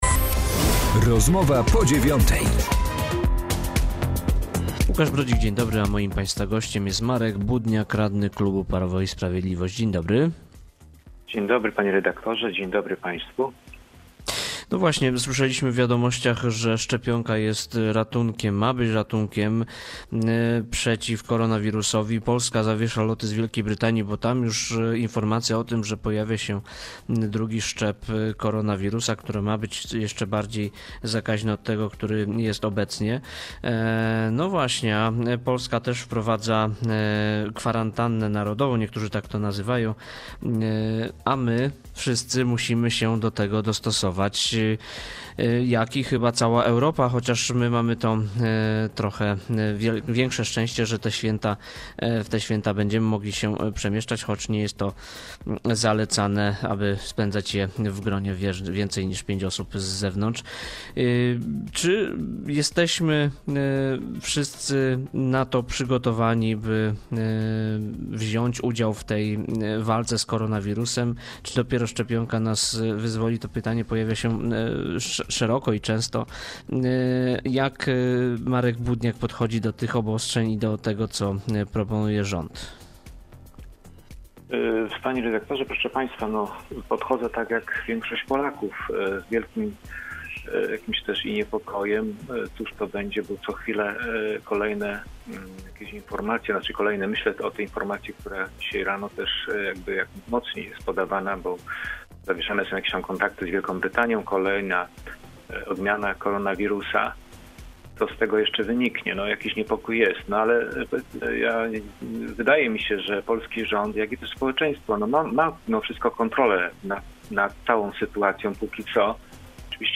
Z radnym klubu Prawo i Sprawiedliwość rozmawia